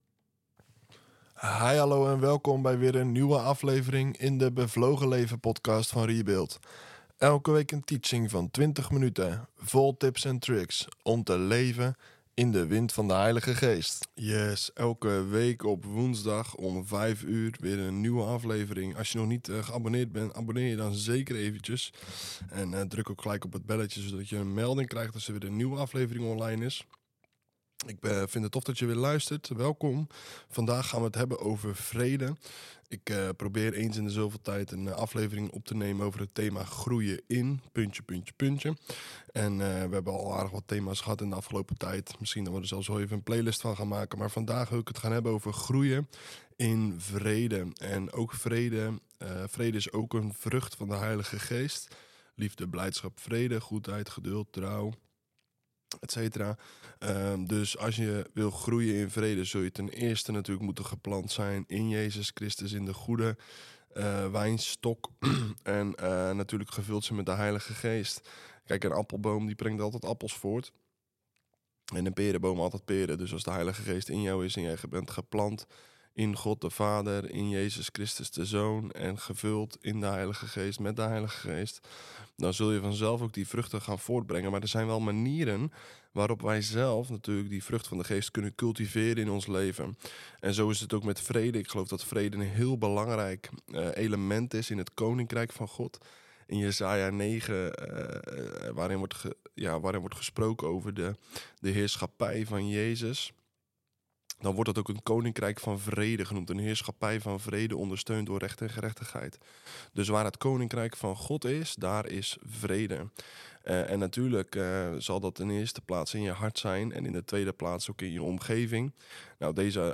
Een wekelijkse teaching van twintig minuten, vol tips & tricks om te leven in de wind van de Heilige Geest!